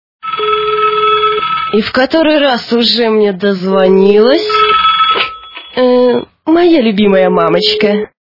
» Звуки » Люди фразы » И в который раз мне уже дозвонилась? - Моя любимая мамочка
При прослушивании И в который раз мне уже дозвонилась? - Моя любимая мамочка качество понижено и присутствуют гудки.